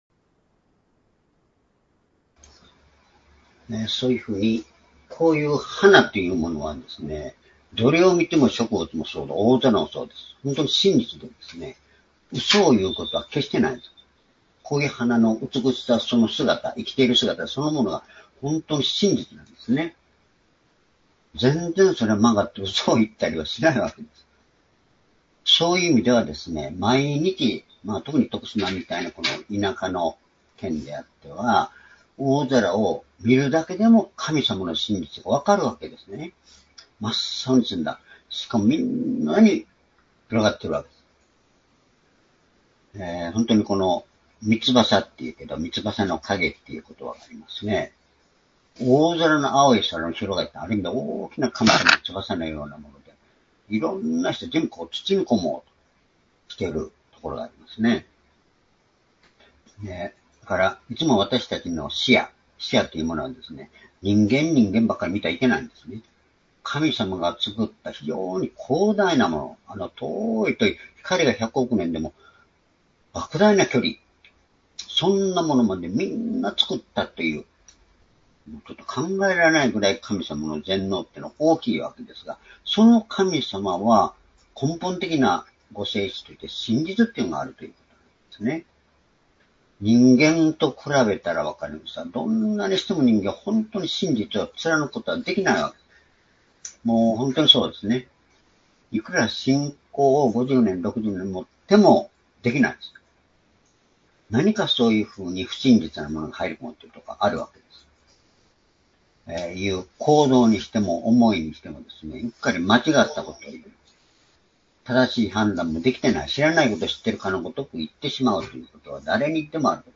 「神の真実」―第1ヨハネの手紙１章5節～１０節－２０２５年7月6日（主日礼拝）